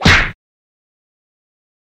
На этой странице представлены звуки подзатыльника – резкие, неожиданные аудиоэффекты.
Как будто из комедийного фильма